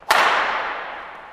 Badmitton | Sneak On The Lot